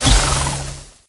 jess_shoot_01.ogg